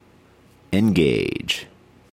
描述：与科幻相关的口头文本样本。
Tag: 语音 英语 科幻 美国航空航天局 电火花 声乐 口语 空间